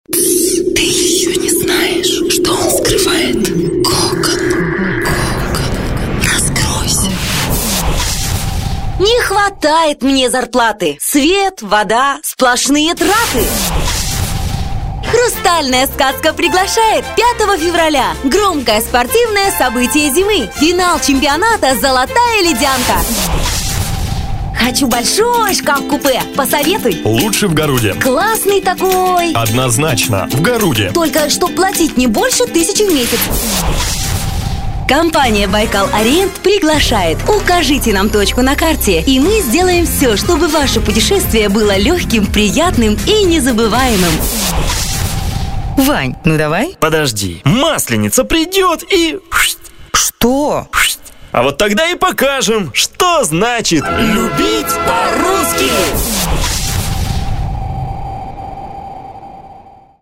С удовольствием прочитаю как информационный текст - динамично или нежно, так и игровой - уверенно и смело.
Микрофон PROAUDIO UBL-D. Микшер.